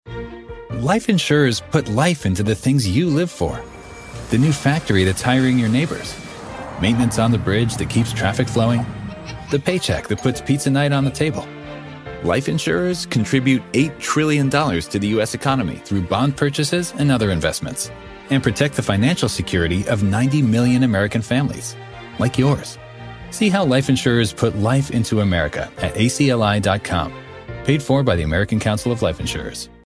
Rich, Relatable Baritone Voice.
ACLI Podcast and Radio Ad
Professional home studio with Source Connect Standard. Sennheiser MKH-416 and Neumann TLM 103 microphones.